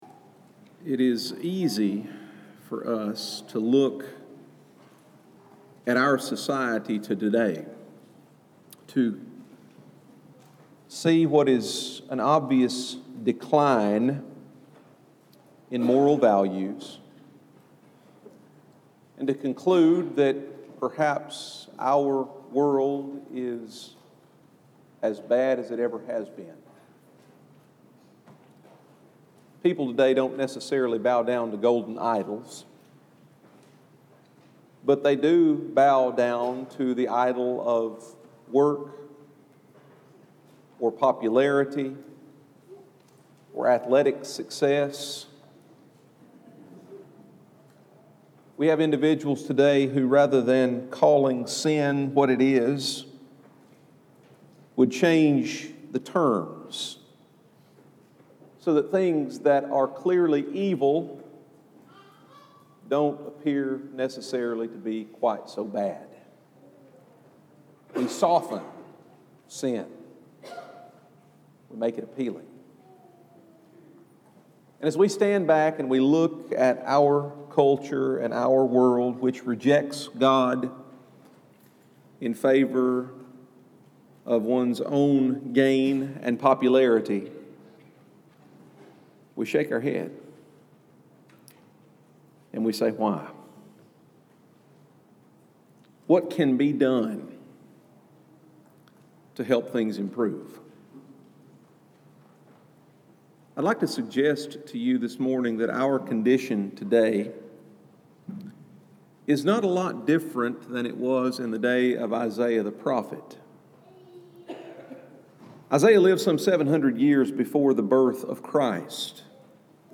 delivers a lesson centered on Isaiah 9:6!